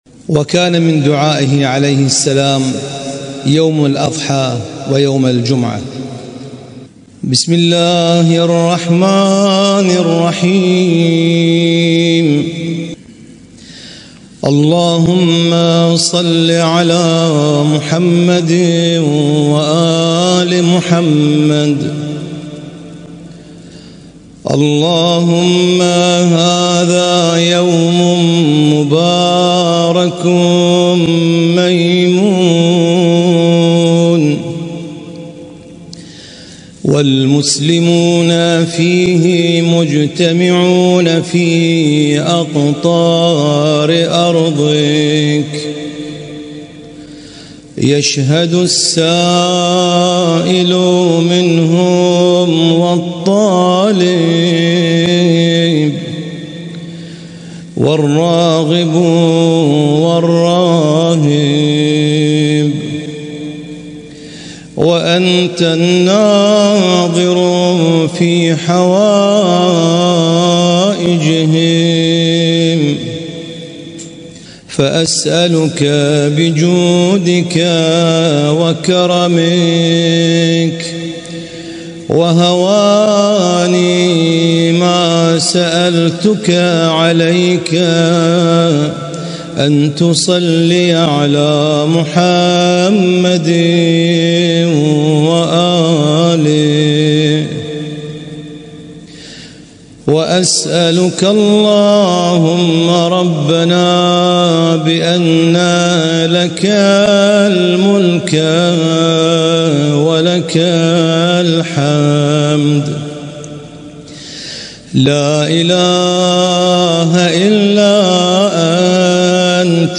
القارئ
اسم التصنيف: المـكتبة الصــوتيه >> الصحيفة السجادية >> الادعية السجادية